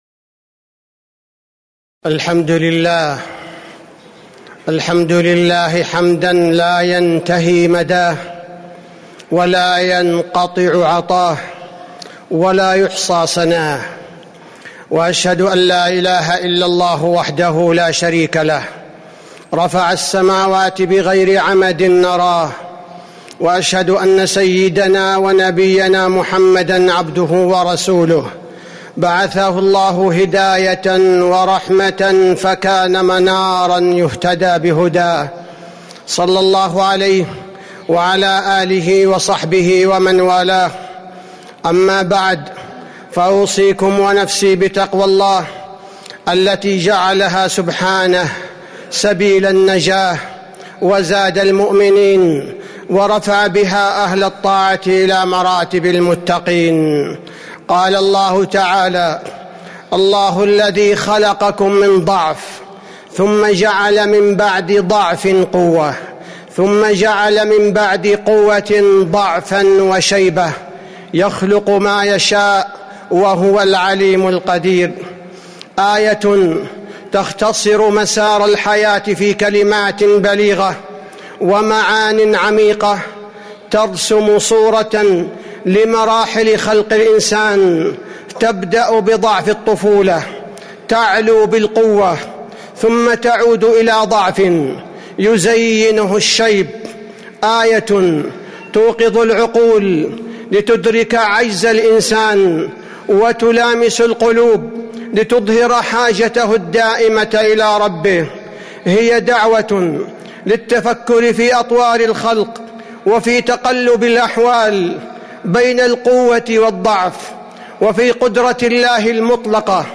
تاريخ النشر ١٧ رجب ١٤٤٦ هـ المكان: المسجد النبوي الشيخ: فضيلة الشيخ عبدالباري الثبيتي فضيلة الشيخ عبدالباري الثبيتي ثم جعل من بعد قوةٍ ضعفاً وشيبة